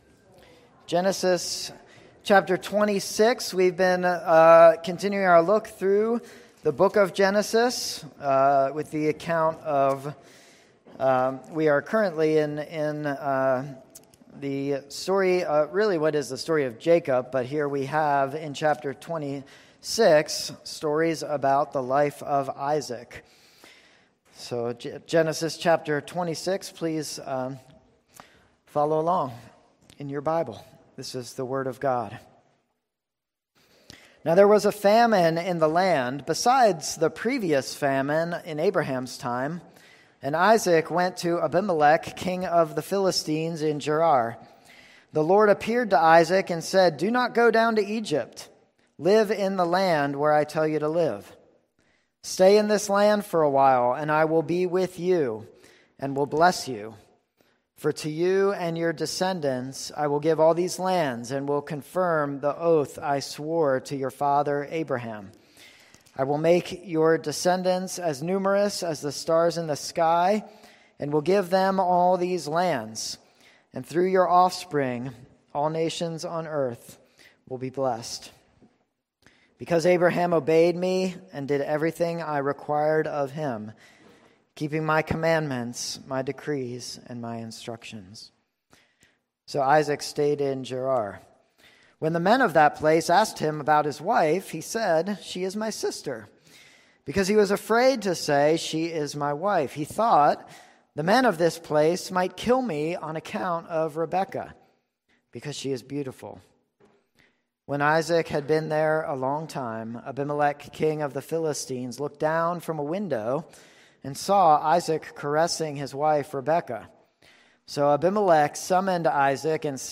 In this sermon from Philippians 3